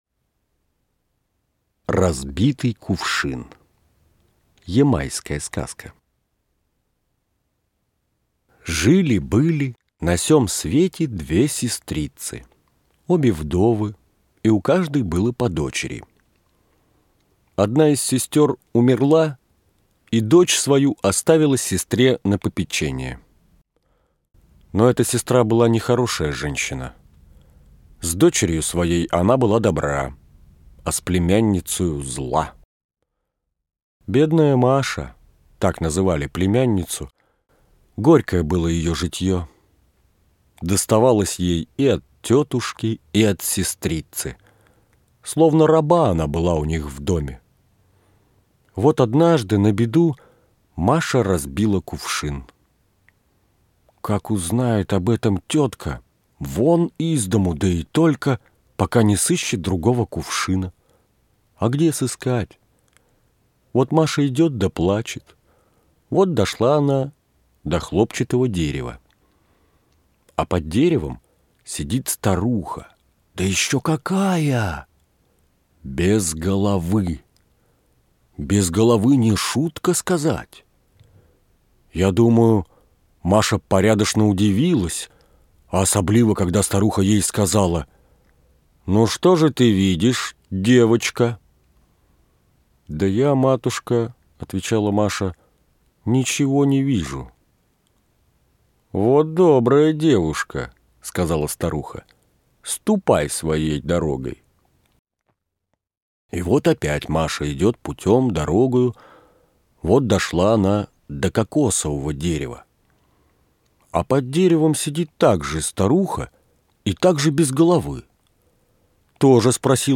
Разбитый кувшин (ямайская сказка) - аудиосказка Одоевского В.Ф. Сказка про злую тетку и трудолюбивую племянницу.